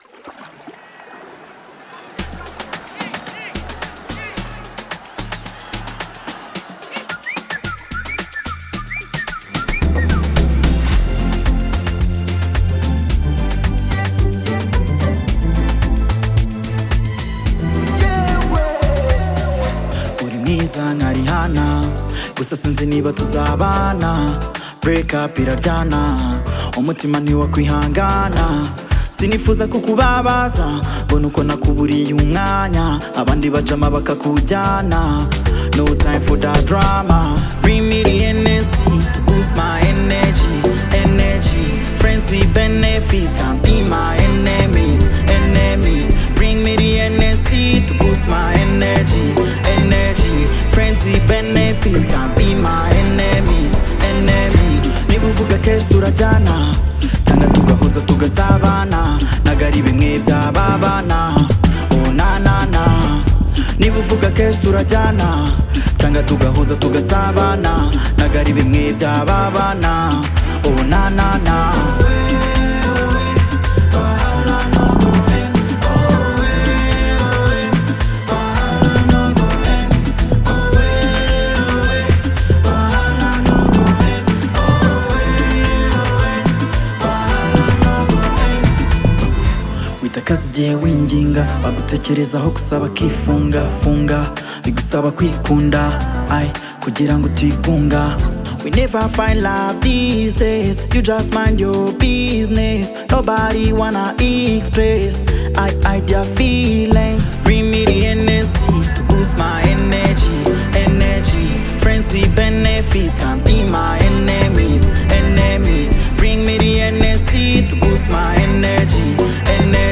blends Afrobeat with Unique sounds
With its upbeat tempo and catchy sounds